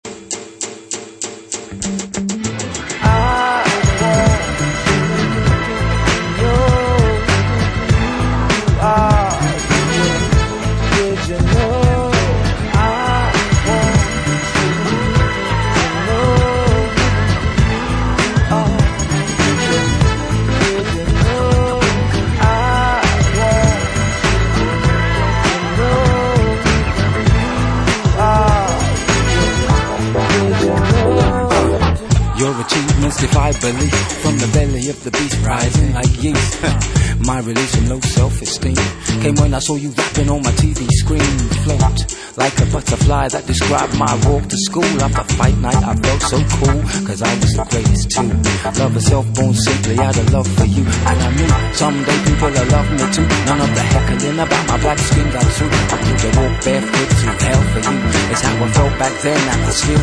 Styl: House, Lounge, Breaks/Breakbeat